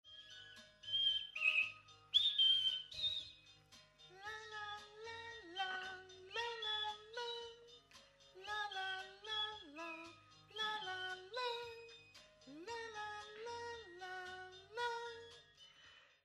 Vocals (4), 255 KB
tb_vocals_4.mp3